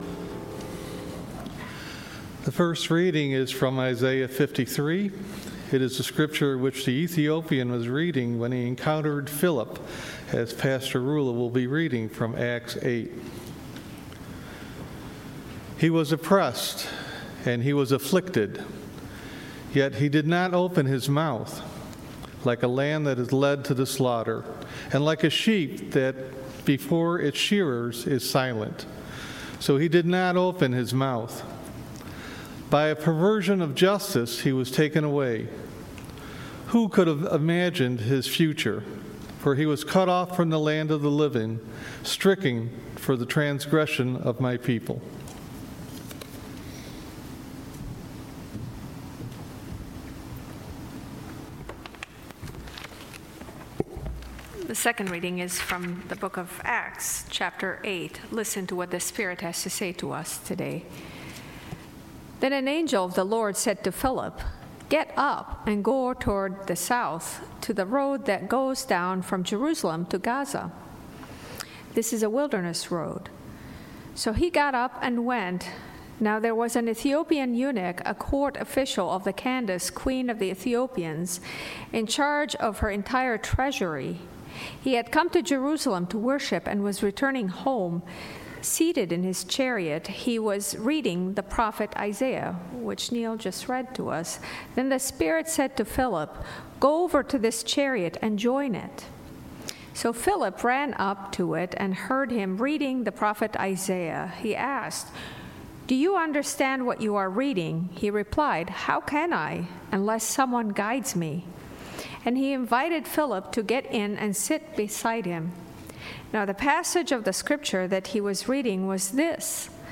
Micah 6:6-8 Service Type: Sunday Worship Service